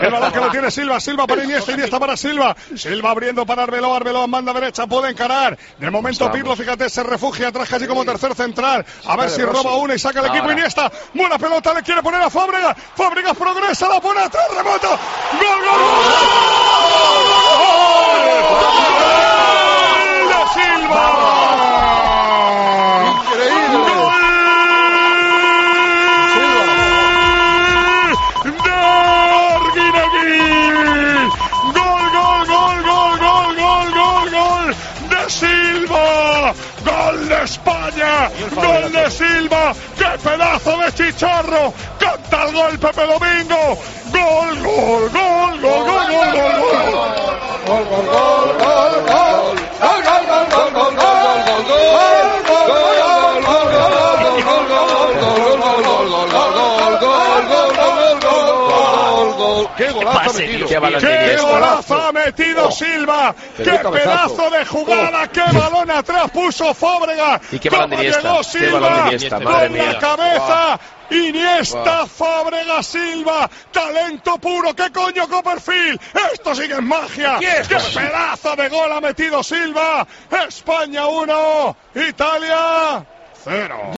La Eurocopa de 2012 fue la última vez que España levantó un gran título internacional, y lo escuchamos con inconfundible voz de Manolo Lama